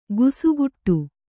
♪ gusuguṭṭtu